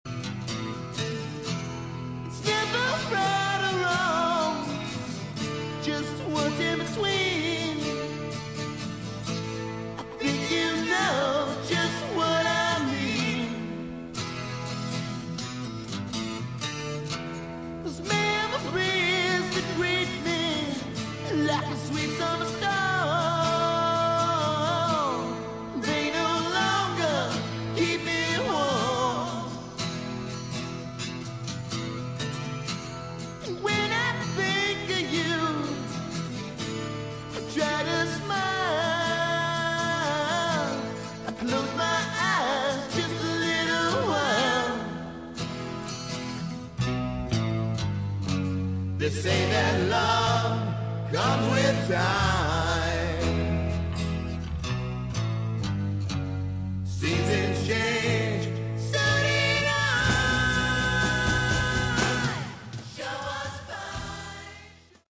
Category: Sleaze Glam
vocals
guitar
bass
drums